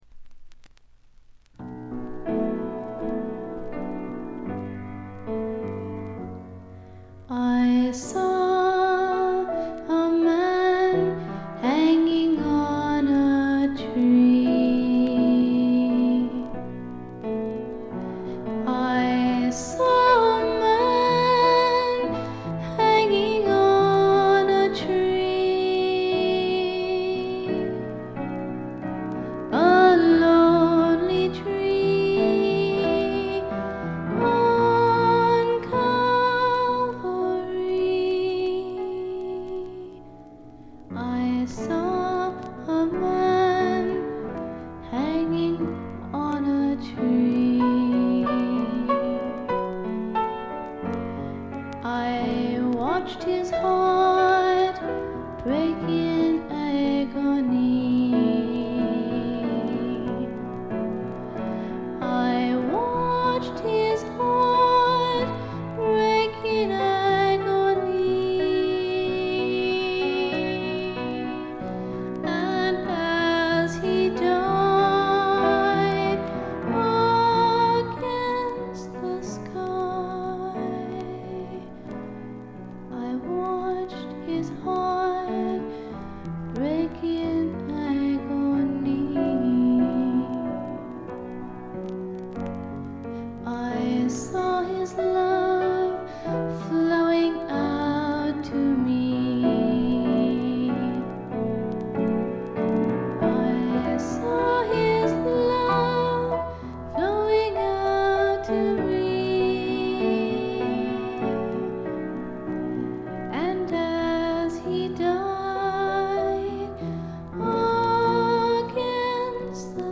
ところどころで軽微なチリプチ。気になるようなノイズはありません。
演奏はほとんどがギターの弾き語りです。
試聴曲は現品からの取り込み音源です。